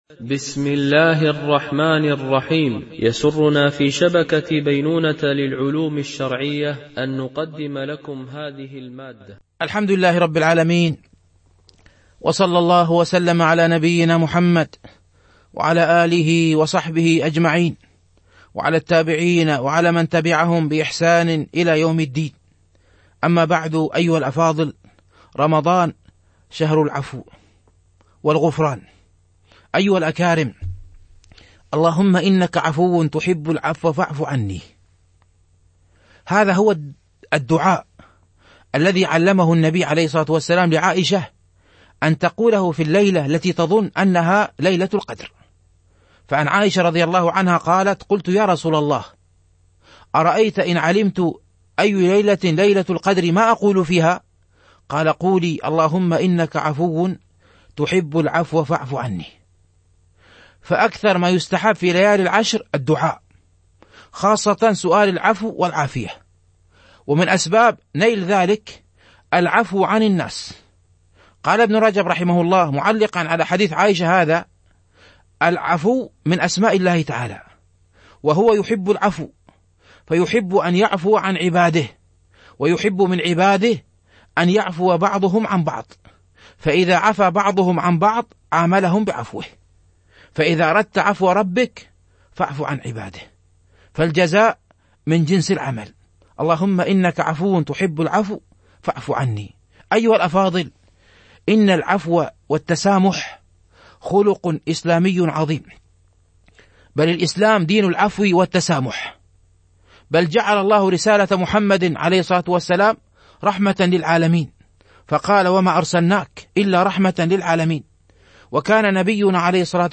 فقه الصائم - الدرس 21